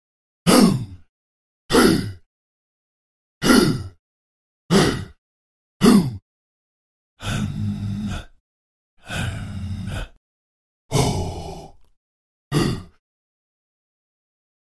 Tiếng hò hét Hử hử… của bộ lạc, thổ dân, dân làng…
Thể loại: Tiếng con người
Description: Âm thanh đặc trưng tiếng hò hét Hử hử, hực hực, hỏ hỏ... thường được thấy trong các điệu nhảy truyền thống, âm thanh chiến đấu, căng thẳng, chào đón người mới của của bộ lạc dân tộc ít người, thổ dân, dân làng trong rừng sâu...
tieng-ho-het-hu-hu-cua-bo-lac-tho-dan-dan-lang-www_tiengdong_com.mp3